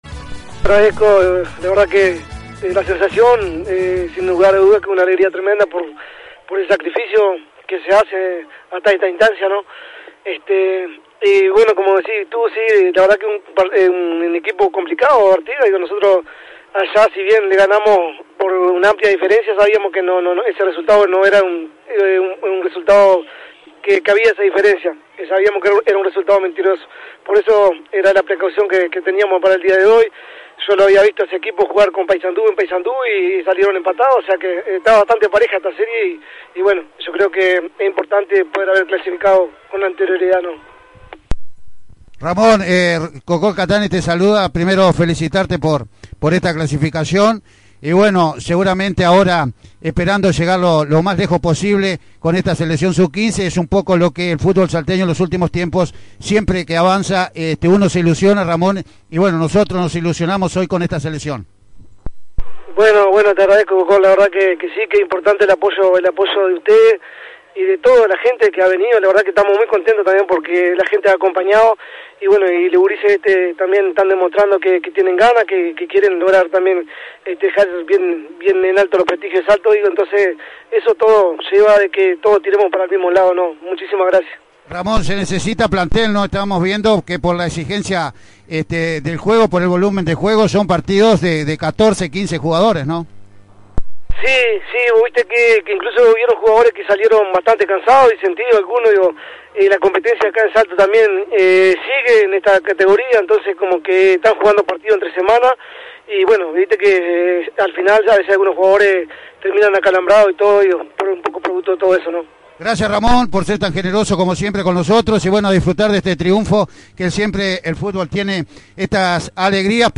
Una vez finalizado el partido y consumada la victoria de la selección salteña sub 15 frente a Artigas, dialogamos